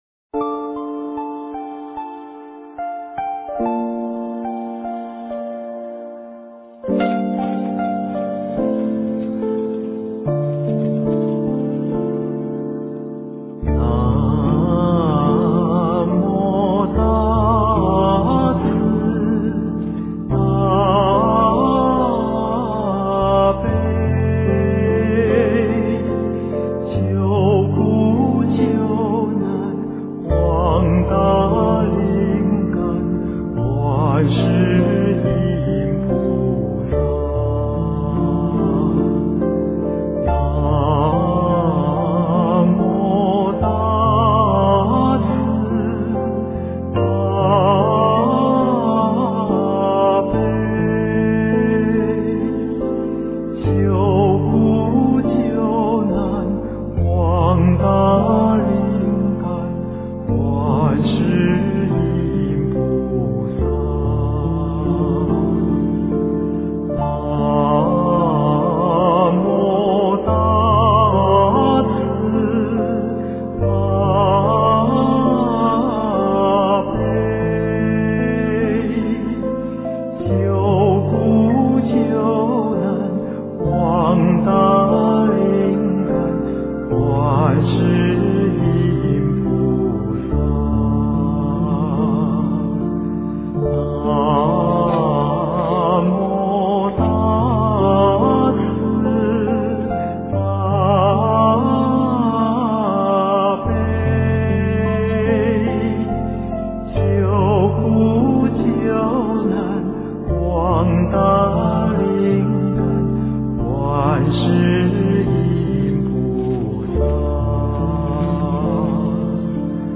南无大慈大悲救苦救难广大灵感观世音菩萨--佛教音乐